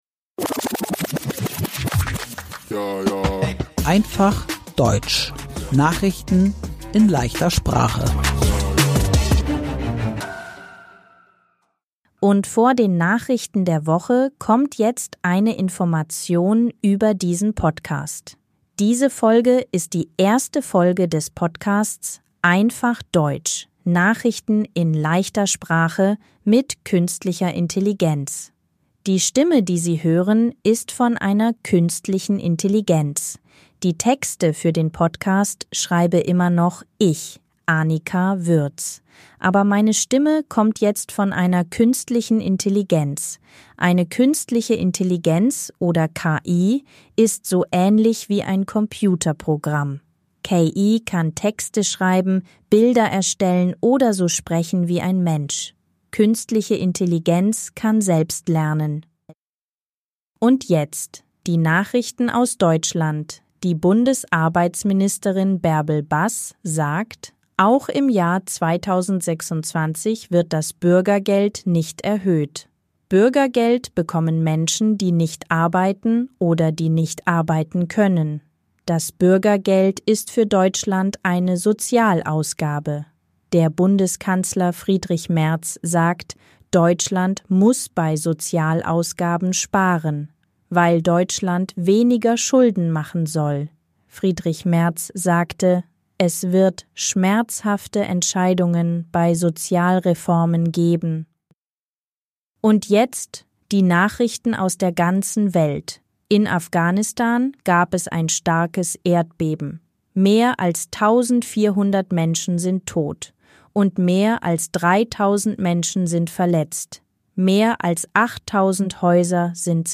Einfaches Deutsch: Nachrichten in leichter Sprache